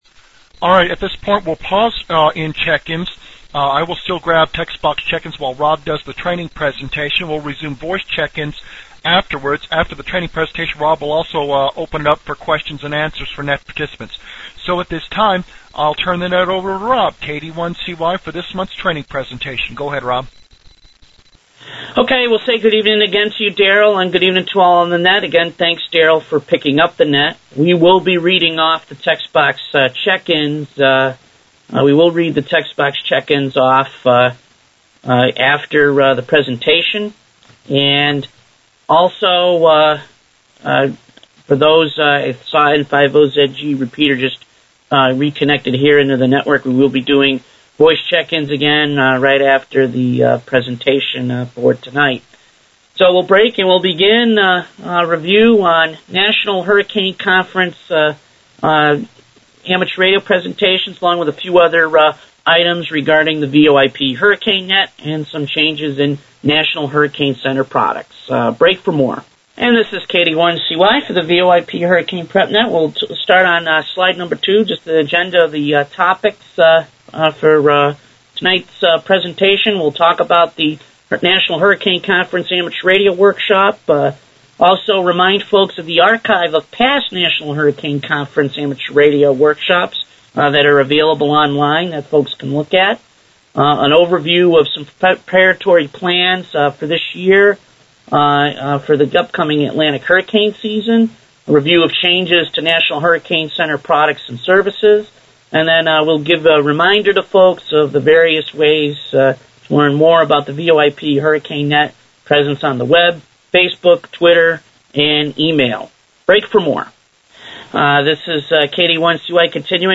Again, for best results, you likely will want to download the Audio Recording of the net (approximately 5.3 Meg in size).